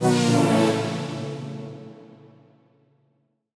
Index of /musicradar/future-rave-samples/Poly Chord Hits/Ramp Down
FR_ZString[dwn]-A.wav